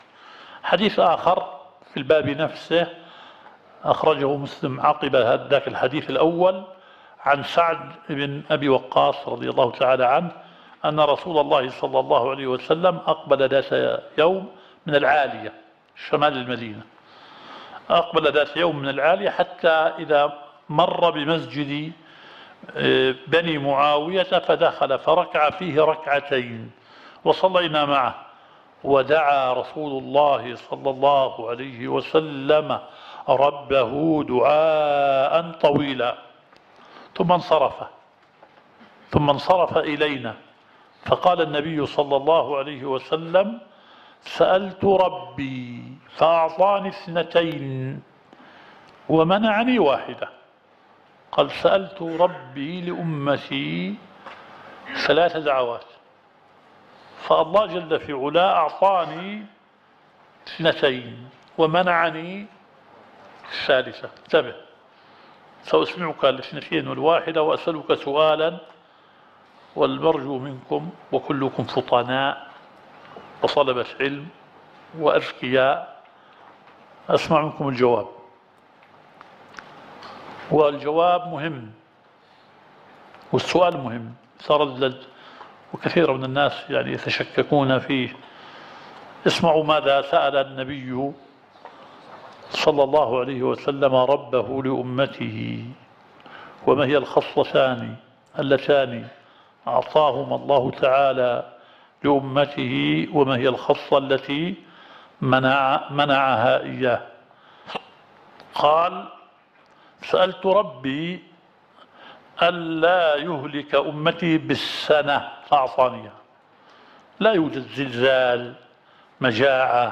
الدورة الشرعية الثالثة للدعاة في اندونيسيا – منهج السلف في التعامل مع الفتن – المحاضرة الثانية.